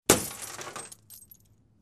Звуки цензуры, мата